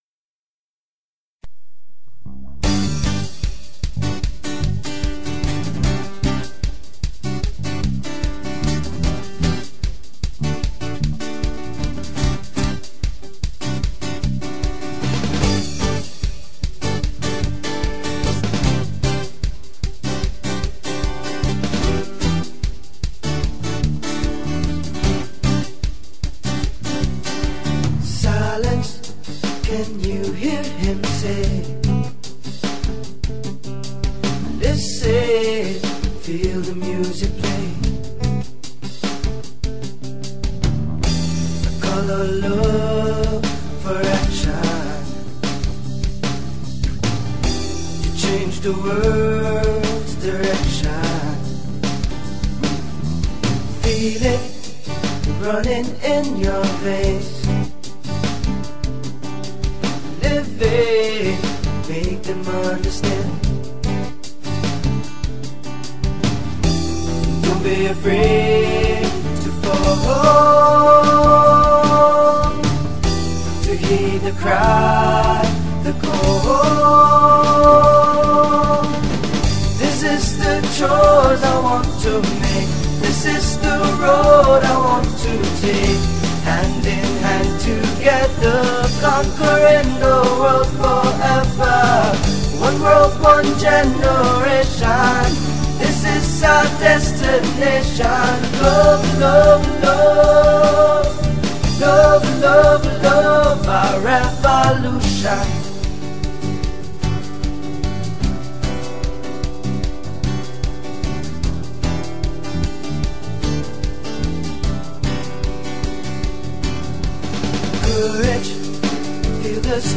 Version basse qualité